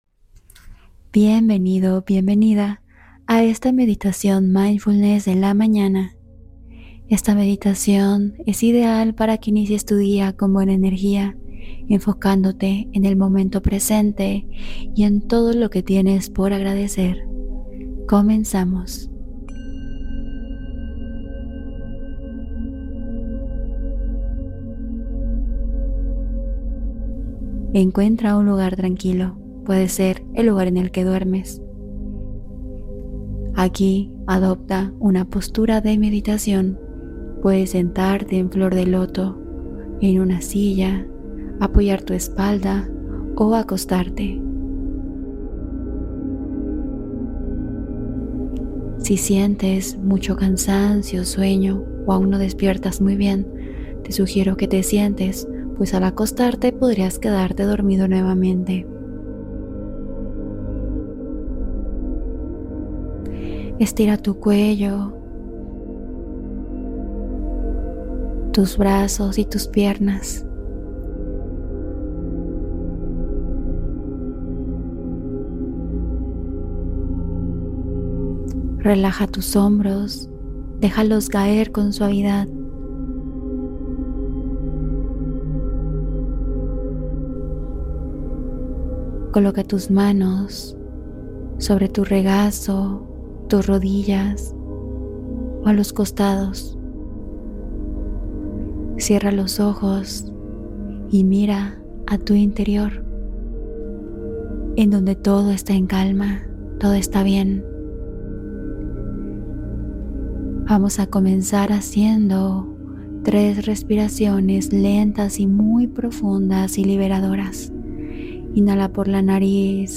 Despierta con Energía Suave: Meditación Guiada de Mañana (15 Minutos)